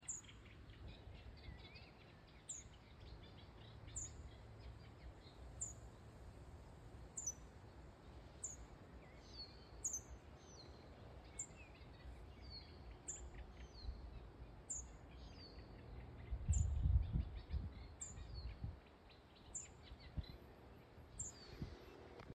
Bird Aves sp., Aves sp.
Administratīvā teritorijaSalacgrīvas novads
StatusSinging male in breeding season